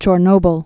(chôr-nōbəl, -bĭl)